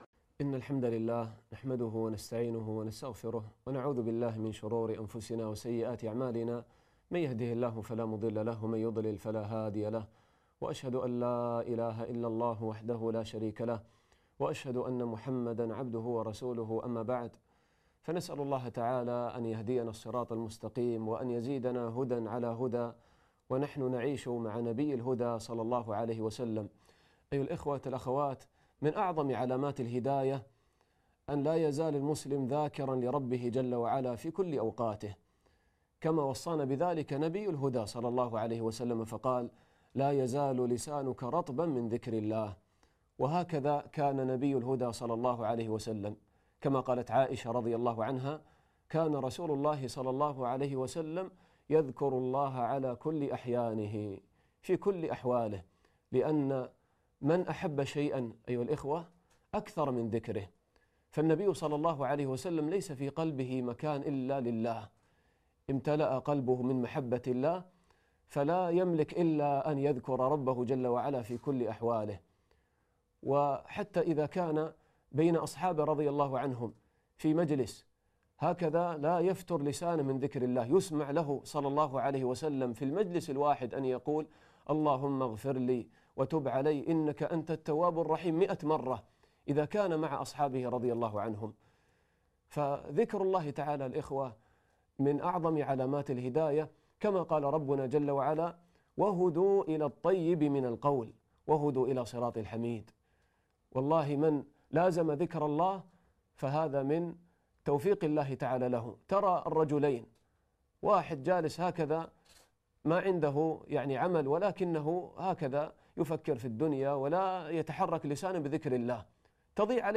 الدرس الثامن عشر